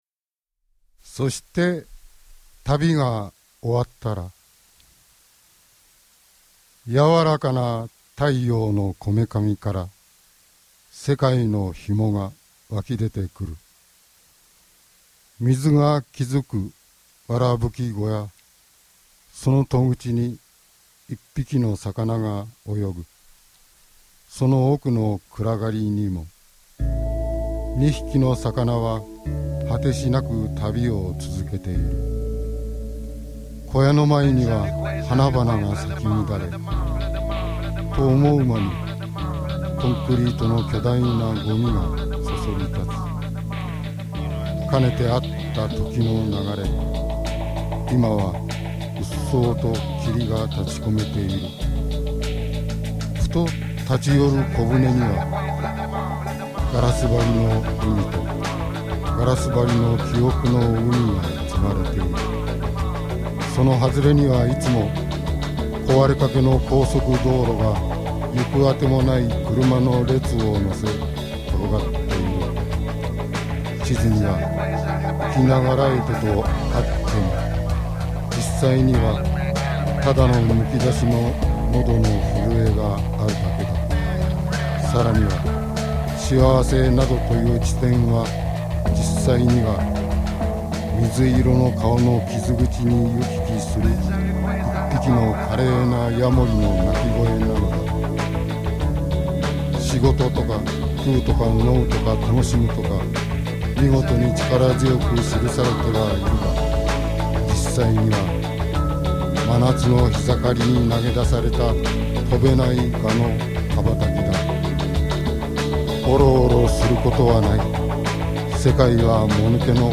冒頭のポエトリーに、ビート、ベース、ギター、キーボードが躍動を与える様に加わっていく温かく緩やかな幕開け。
そこから淡いソウルや染み込む様なジャズの世界を味わいを堪能させてから、アフリカ、沖縄へのナチュラルな音の旅へと誘われる。
※試聴はダイジェストです。